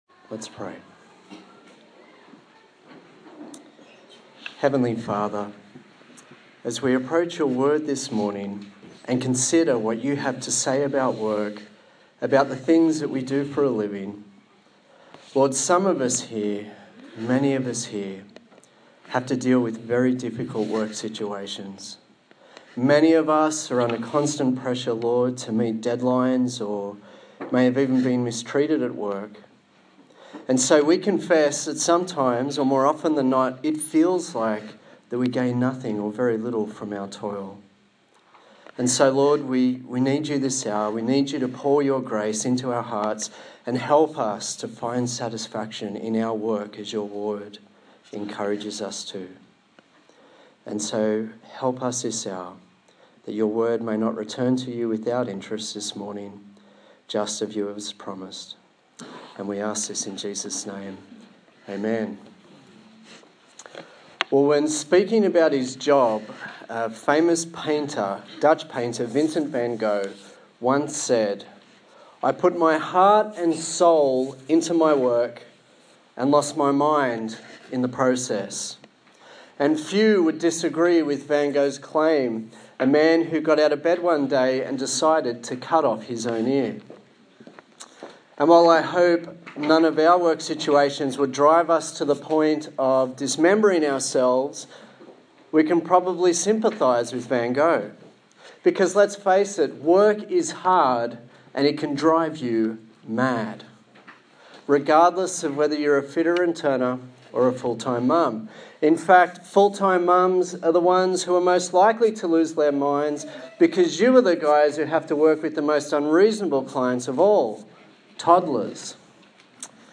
Colossians Passage: Colossians 3:22-4:1 Service Type: Sunday Morning